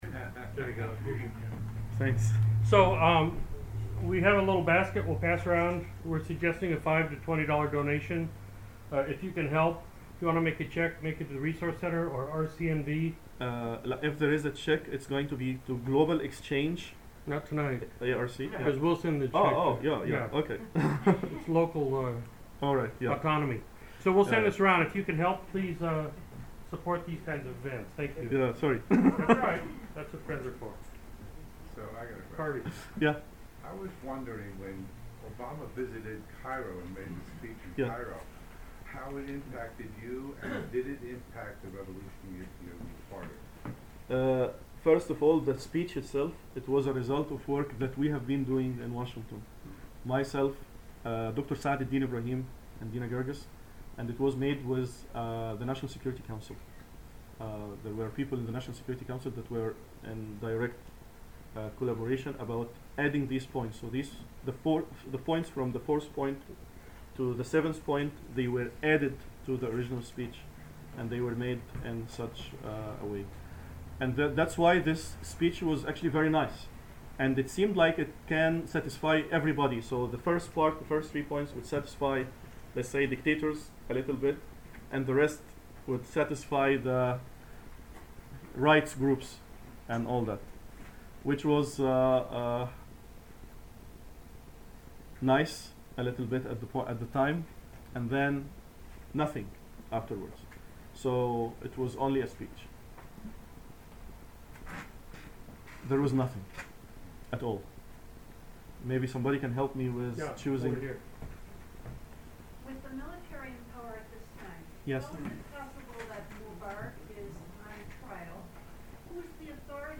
The second audio file is 36 minutes and is the Questions and Answers segment of the evening.